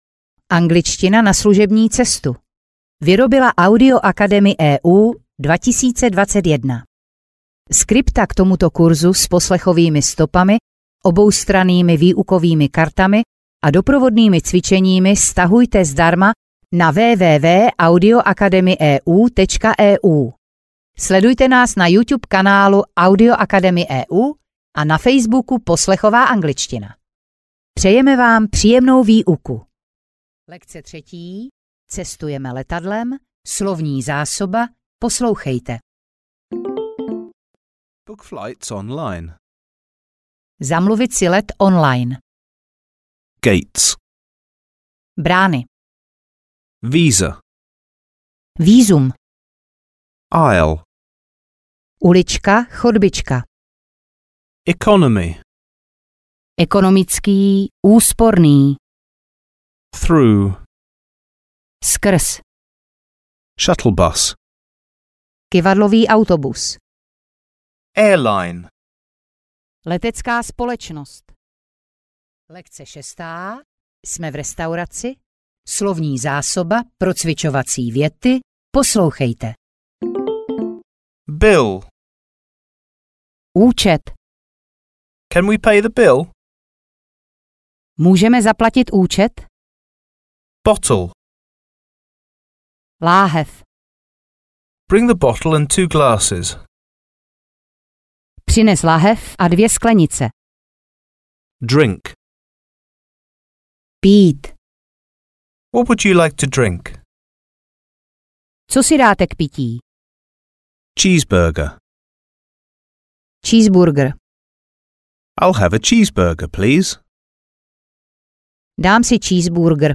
Angličtina na služební cesty audiokniha
Ukázka z knihy